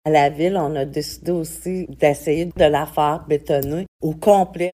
On écoute la mairesse de Maniwaki, Francine Fortin : 11 avril 2023